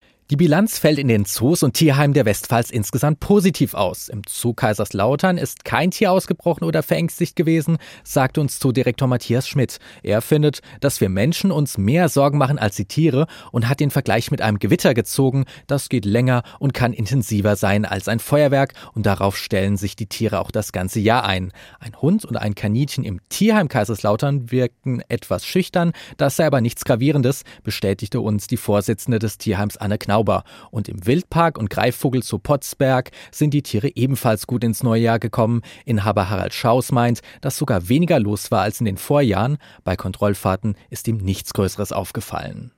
Über dieses Thema berichtete das SWR Studio Kaiserslautern in den SWR4-Regionalnachrichten am 03.01.2025 um 14:30 Uhr.